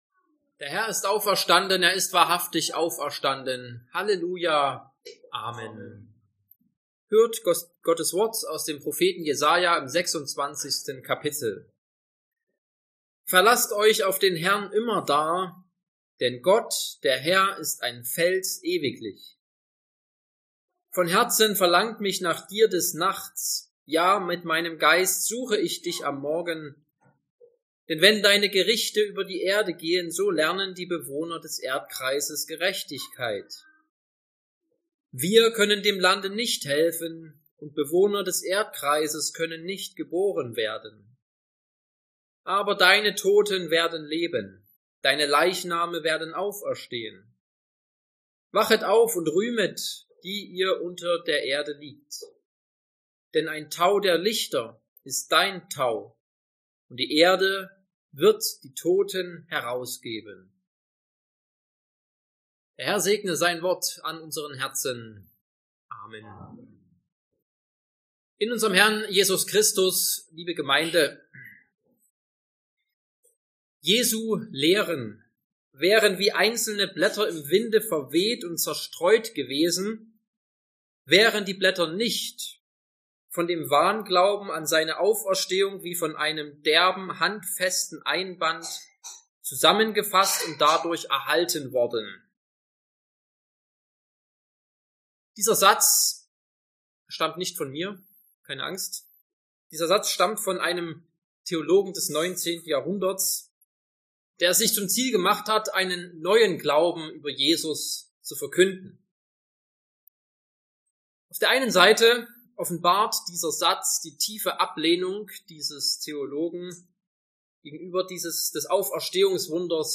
4+9+18+19 Verkündigungsart: Predigt « Ostersonntag 2023 Quasimodogeniti